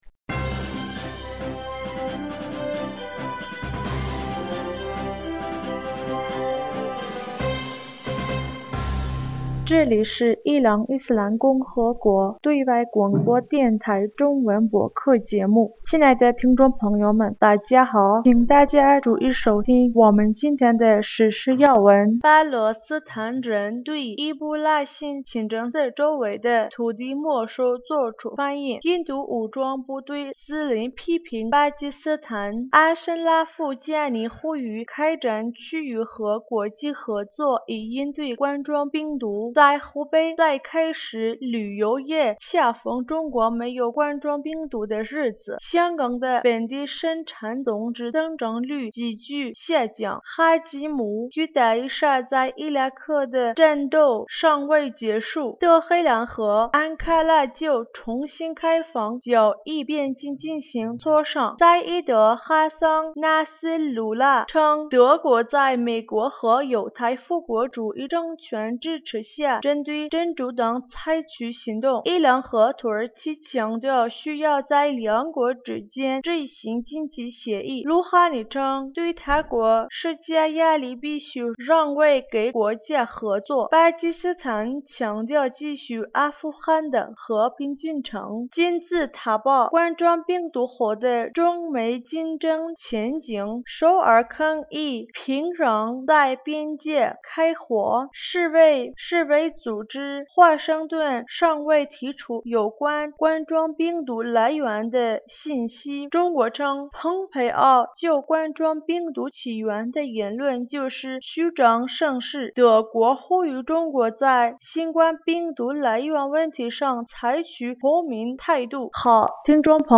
2020年5月5日 新闻